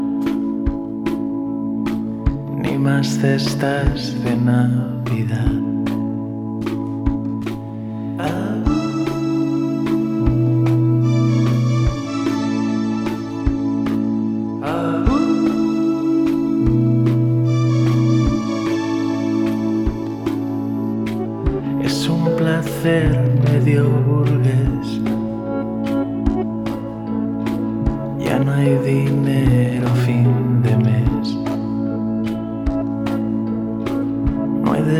Жанр: Поп / Альтернатива